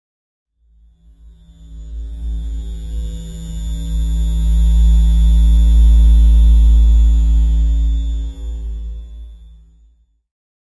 描述：Low bassy pad with an ominous feel.
标签： dark dirge edison pad singlehit
声道立体声